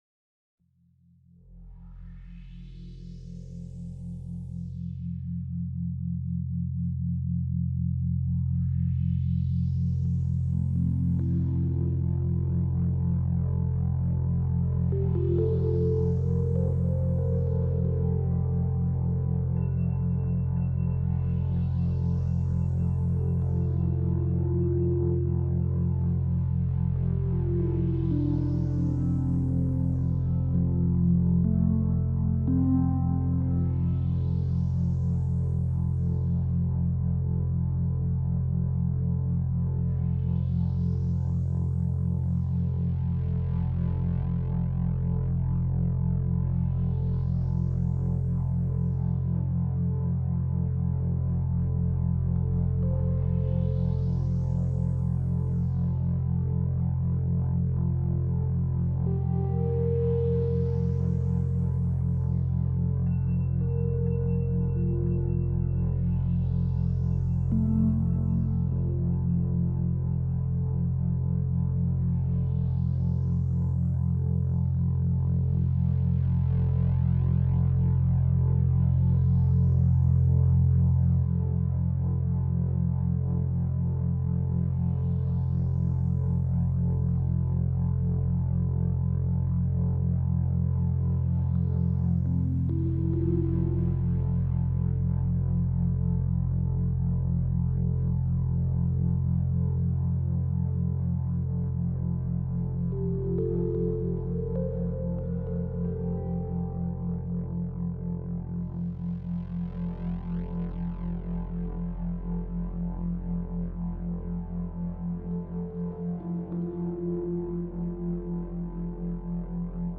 • موسیقی فرکانس 5 هرتز موسیقی فرکانس تتا
• موسیقی فرکانس تتا, موسیقی فرکانس 5 هرتز